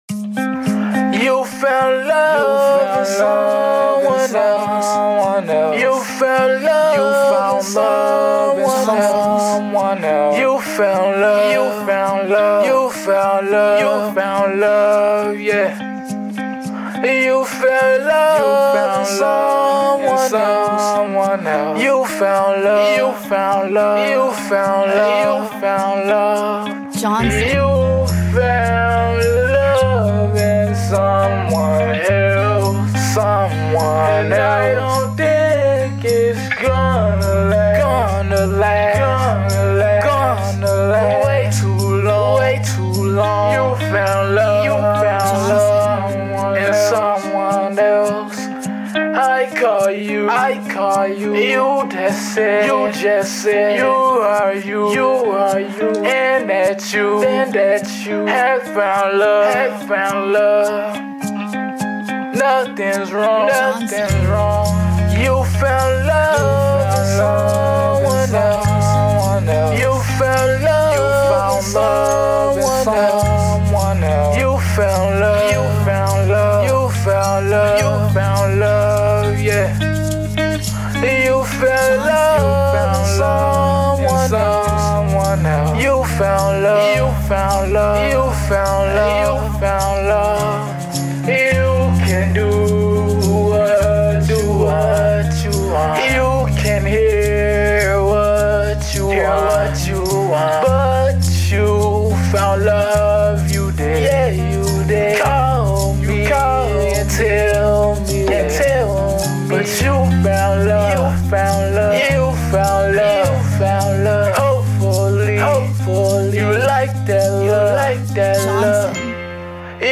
A Alternative Album Mixed With Pop And Rock As Well Enjoy!